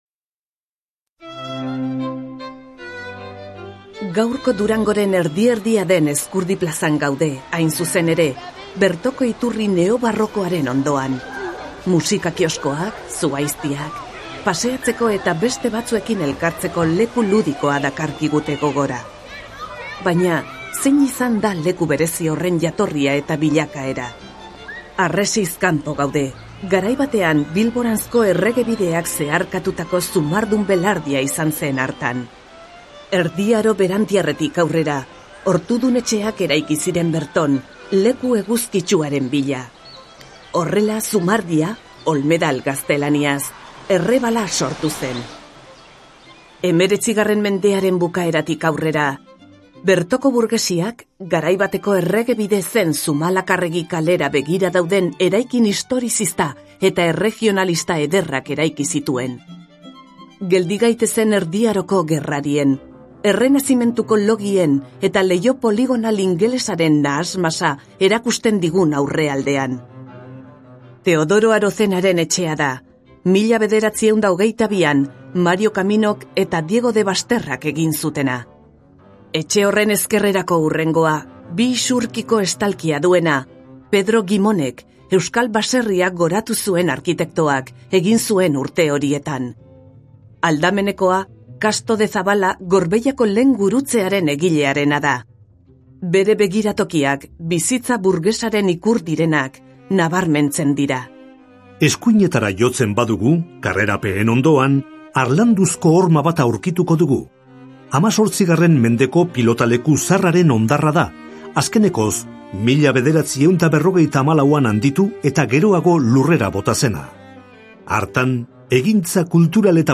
BISITA AUDIOGIDATUAK DURANGON - VISITAS AUDIOGUIADAS EN DURANGO